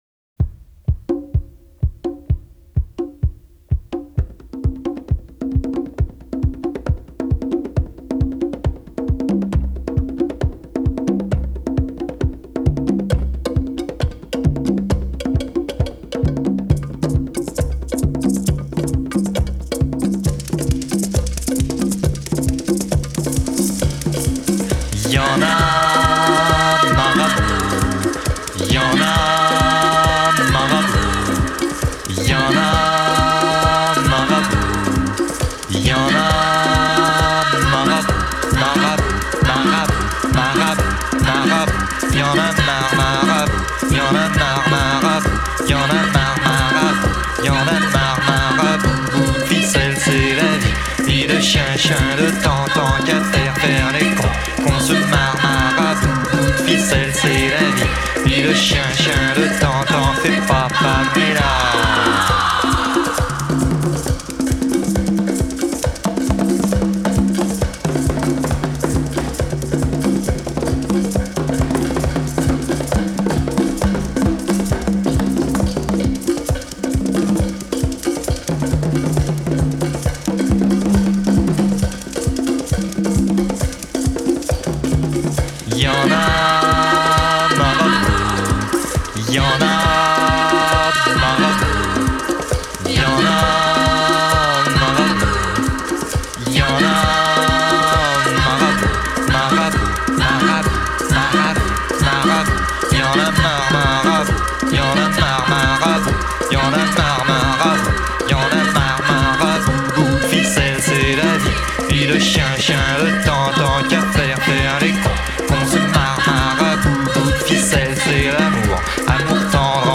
rythmes africains